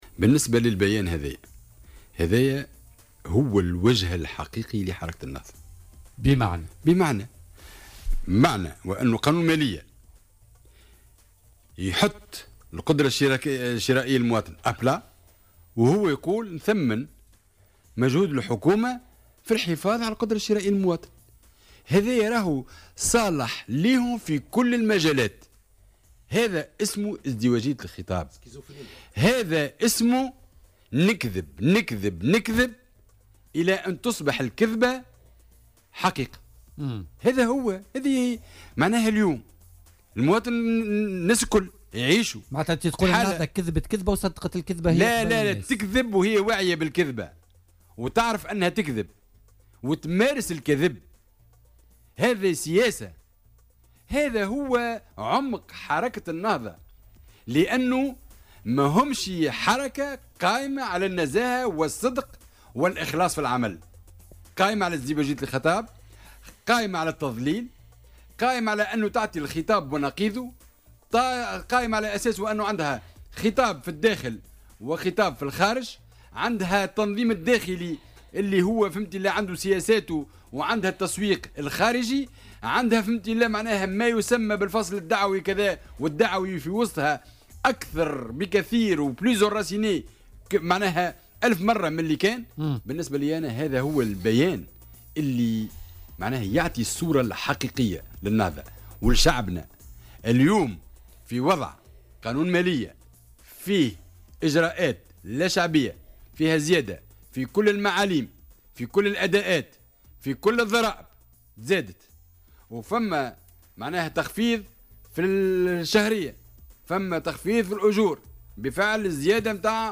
سياستها ليست قائمة على النزاهة والصدق في العمل، وإنما على الكذب والتضليل والازدواجية في الخطاب".وأضاف ضيف "بوليتيكا" اليوم الخميس، أن مشروع قانون المالية لسنة 2018 سيدمّر القدرة الشرائية للمواطن.